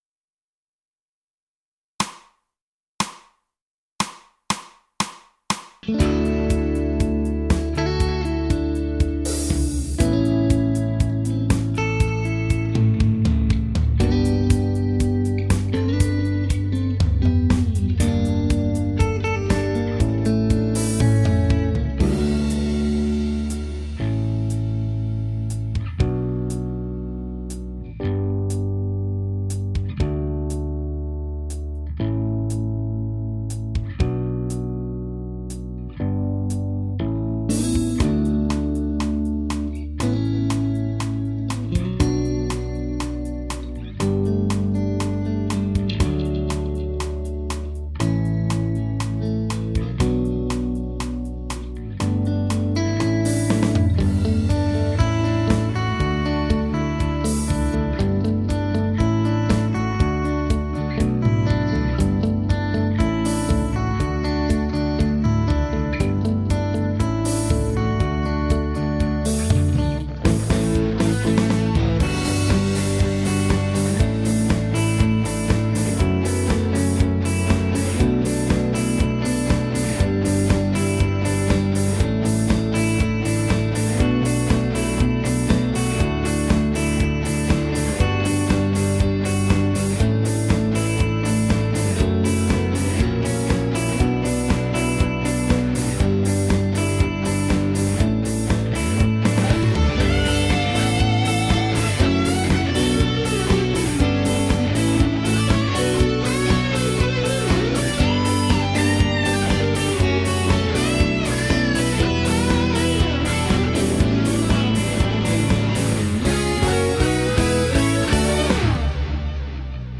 シンセメロ抜きMP3ファイル
シンセメロを抜いたものです。